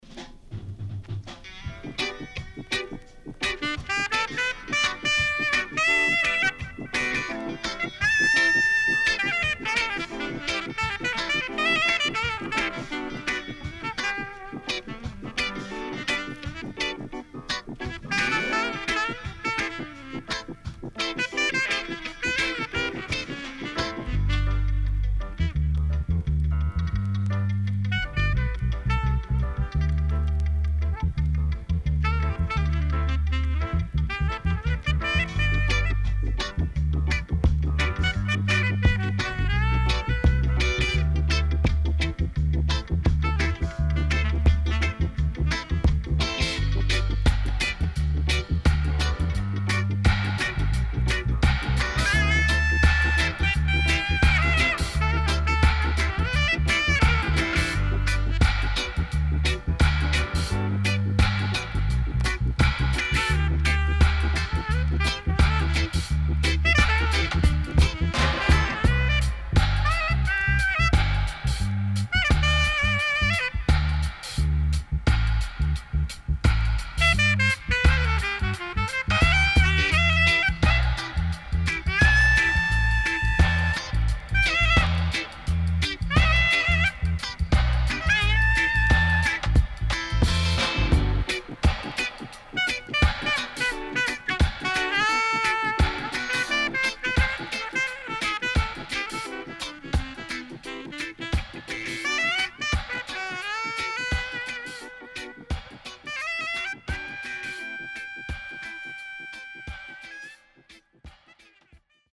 SIDE A:少しチリノイズ、プチノイズ入ります。